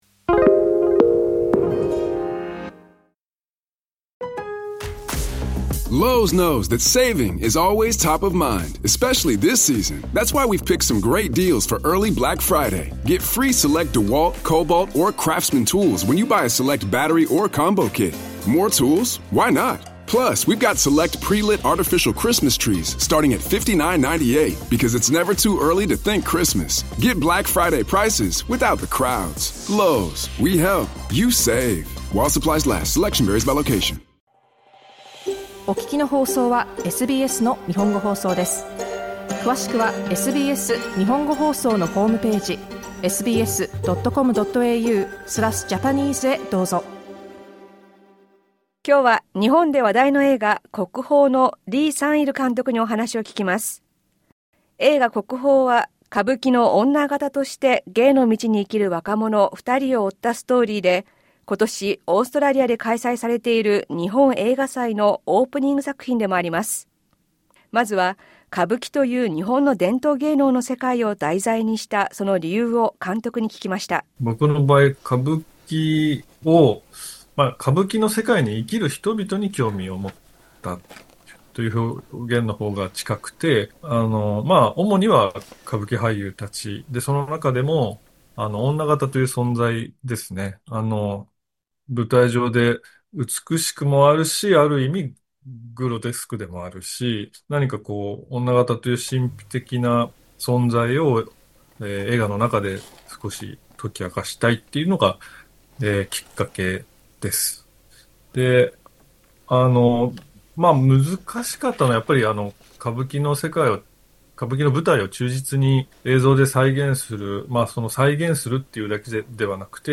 Kokuhō: interview with director Lee Sang-il, Japanese Film Festival 2025
The film is set in the world of Japanese traditional performing arts, Kabuki. We spoke to the director Lee Sang-il.